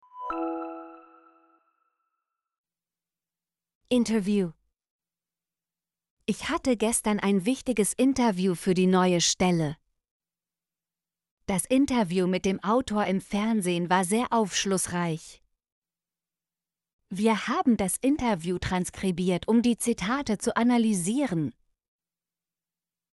interview - Example Sentences & Pronunciation, German Frequency List